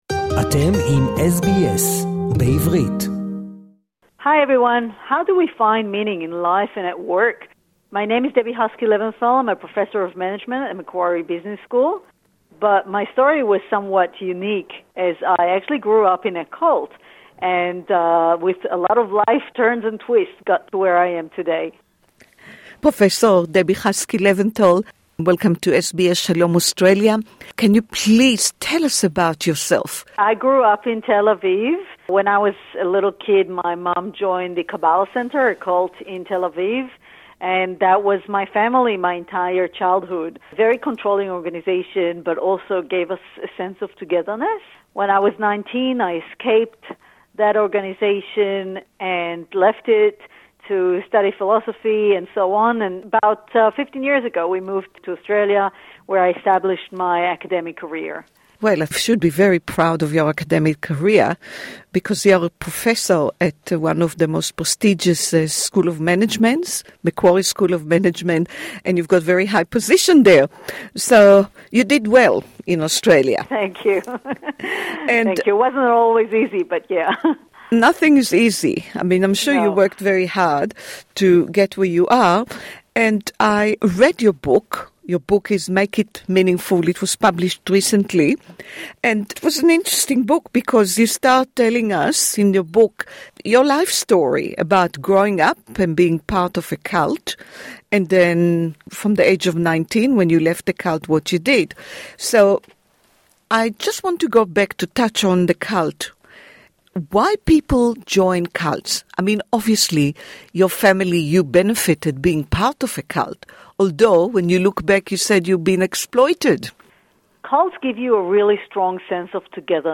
This interview is in English